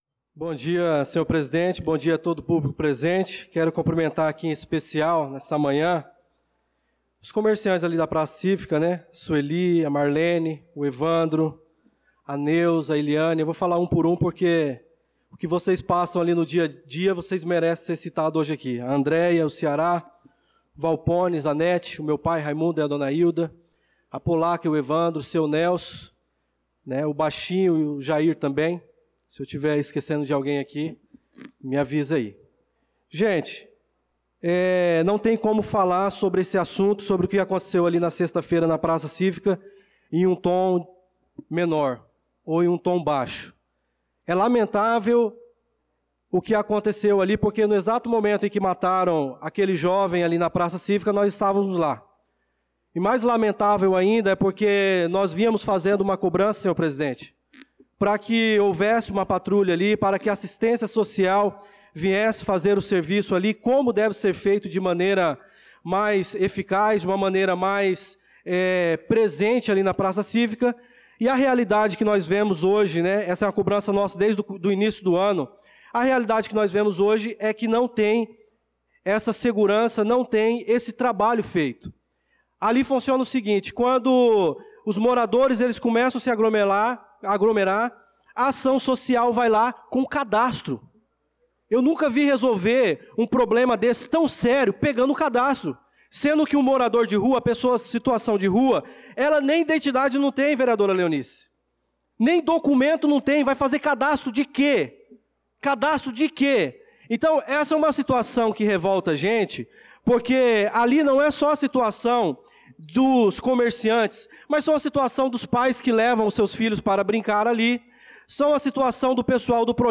Pronunciamento do vereador Darlan Carvalho na Sessão Ordinária do dia 02/06/2025